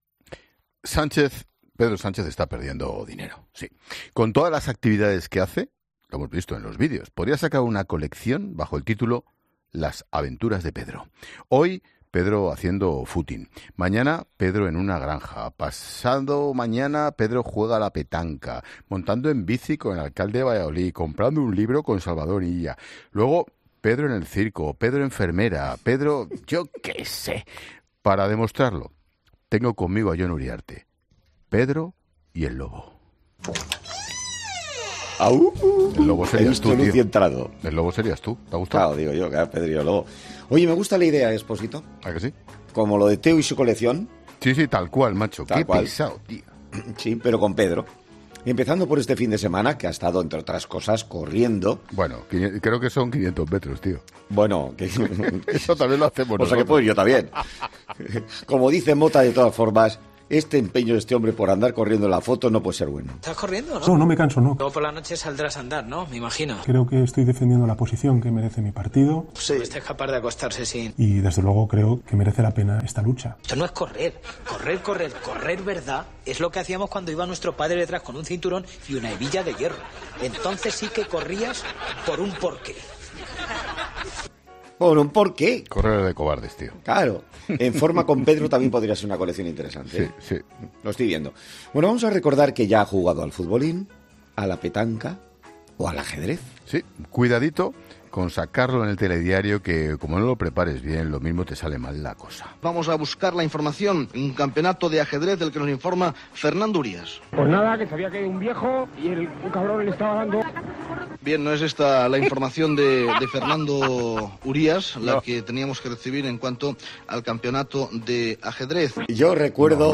Casualmente el director de La Linterna no podía contener la risa mientras comentaba con su compañera la distancia exacta que había recorrido en realidad el líder del Ejecutivo en las imágenes que ha compartido.